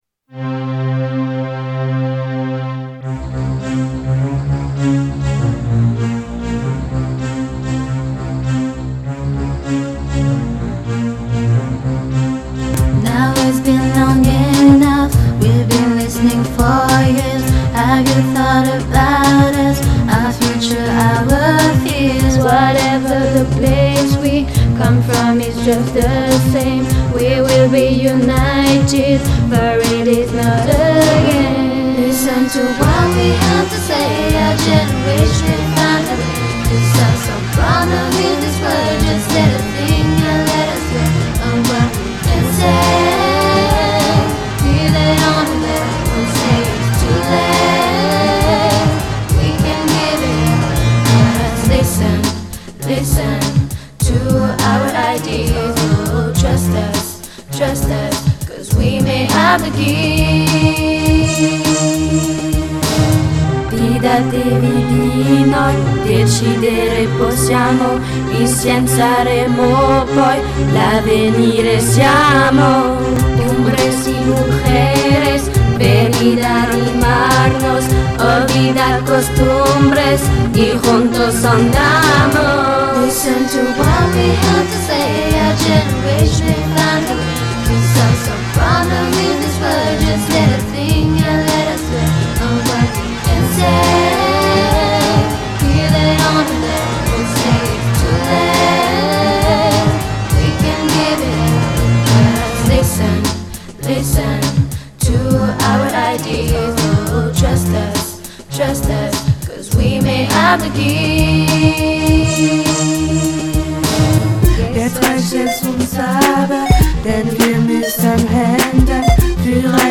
En deux heures et demie, les textes étaient écrits, les volontaires répétaient les chants et tout fut enregistré, puis mixé dans l'après-midi.